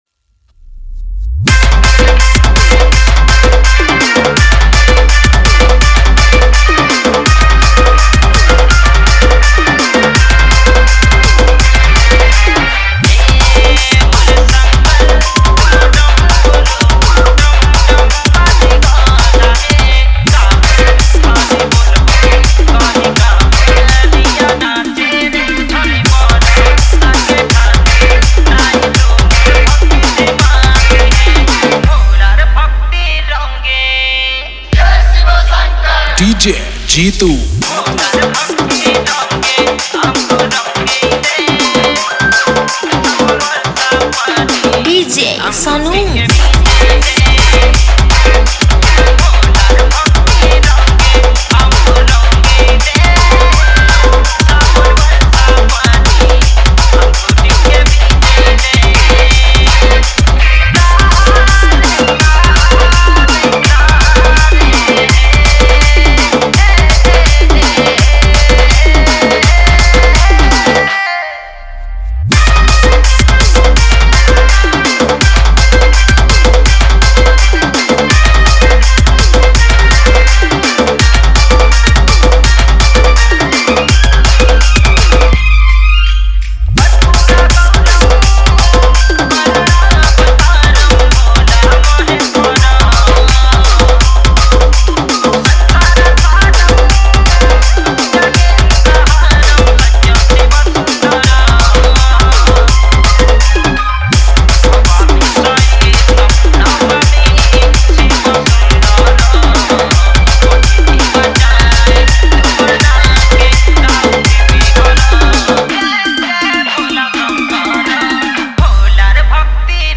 Bolbum Special Dj Song